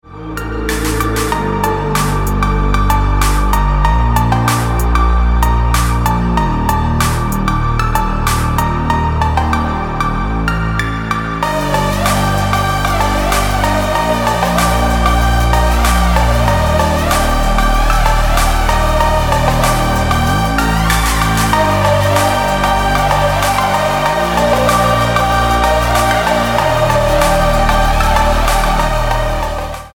Phonk Music